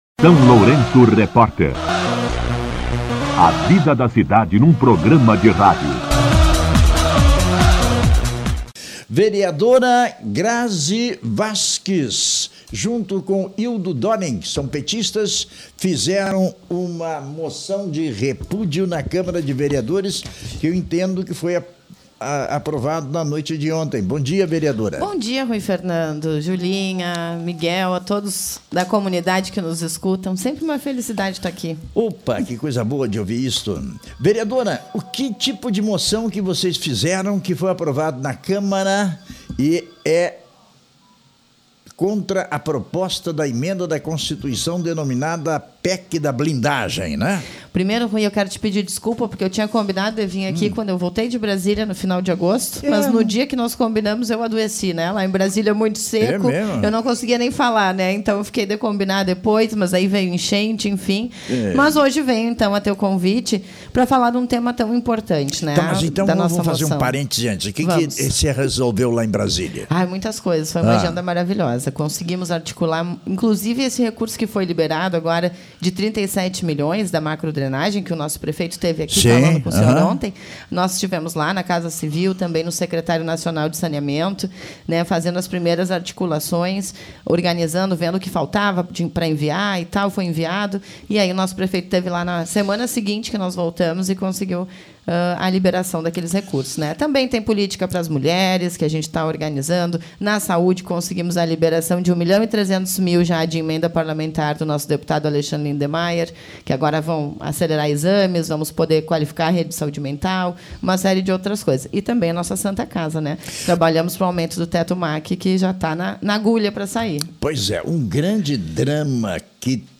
Entrevista com a Vereadora Grazi Vasques
A vereadora Grazi Vasques (PT) esteve no SLR RÁDIO nesta terça-feira (23) para falar sobre a Moção de Repúdio à Proposta de Emenda à Constituição nº 3/2021, conhecida como “PEC da Blindagem”.